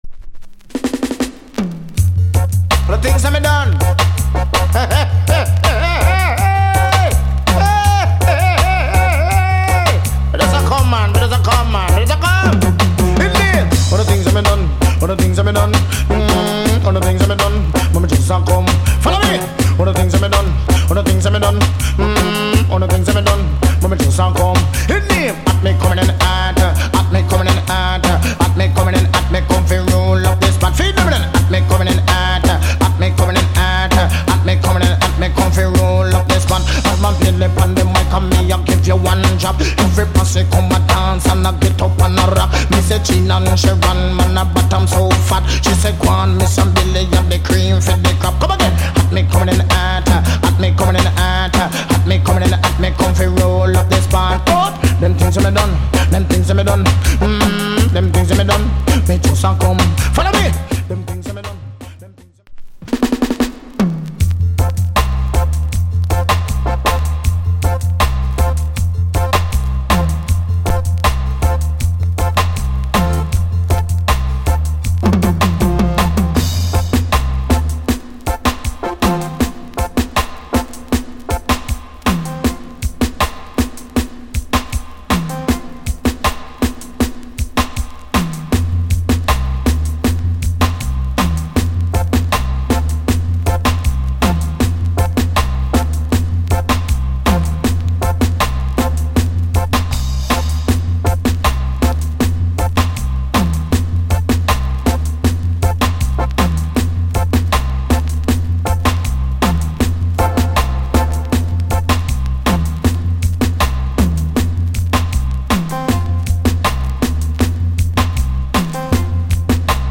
*'87 Big Dance Hall Classic!